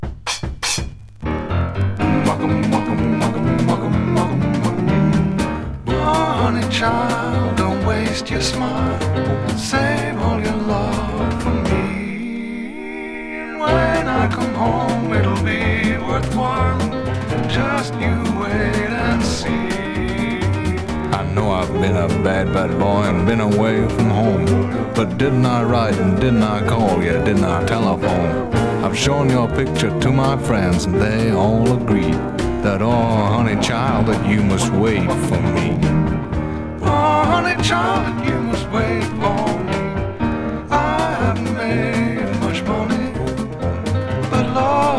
Pop, Rock, Vocal　UK　12inchレコード　33rpm　Stereo